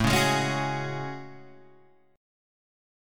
A Major 13th